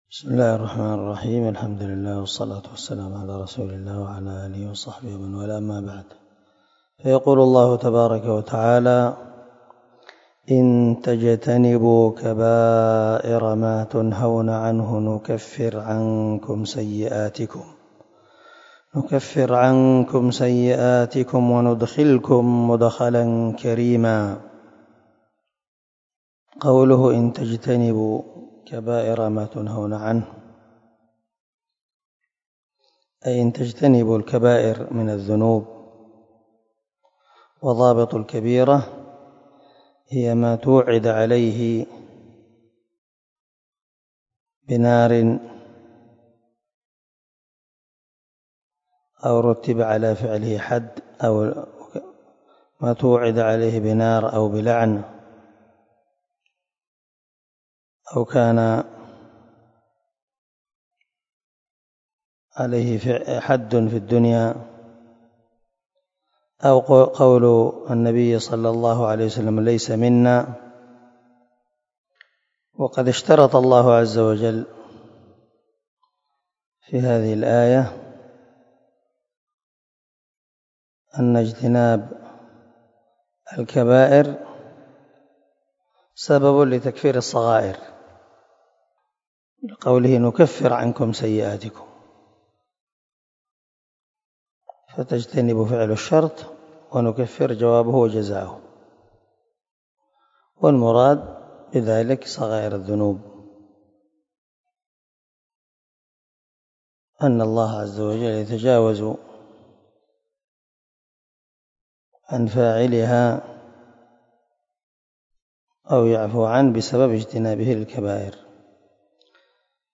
257الدرس 25 تفسير آية ( 31 - 32 ) من سورة النساء من تفسير القران الكريم مع قراءة لتفسير السعدي